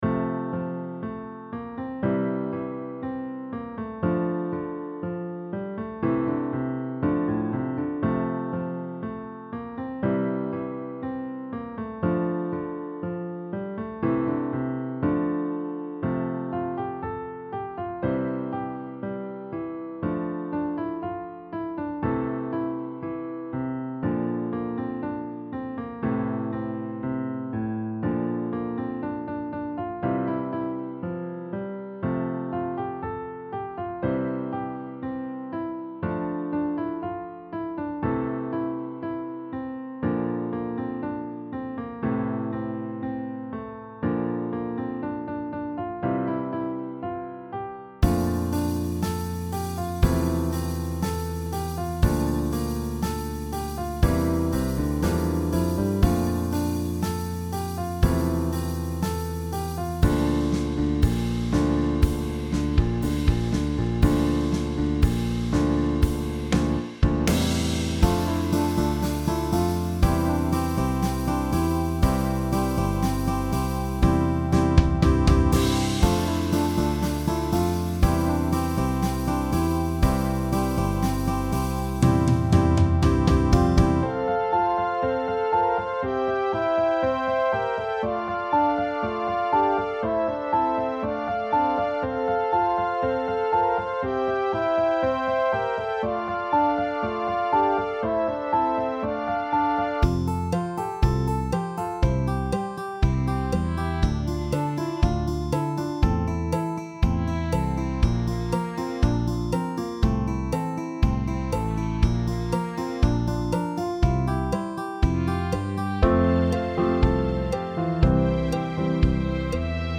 AcousticBallad